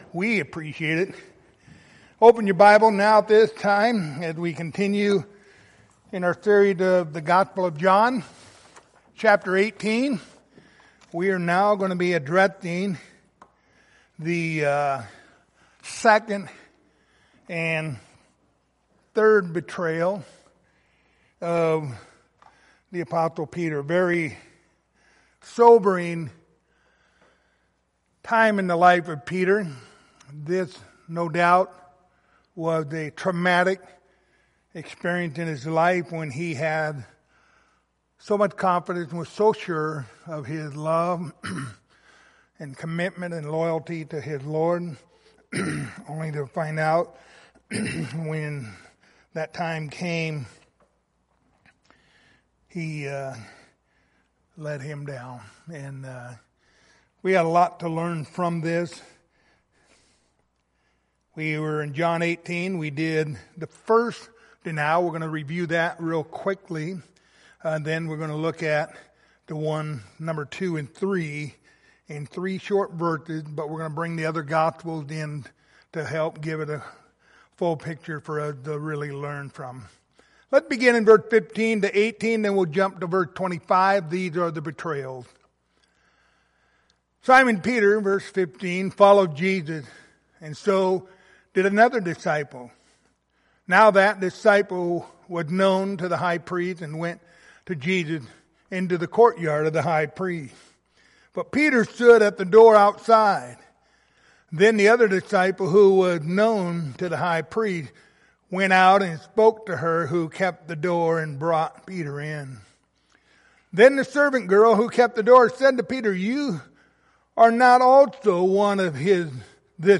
Passage: John 18:15-25 Service Type: Wednesday Evening Topics